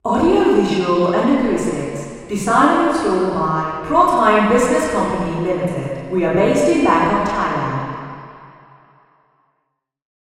Below are auralizations for the four test positions in the study.
Receiver 02 Female Talker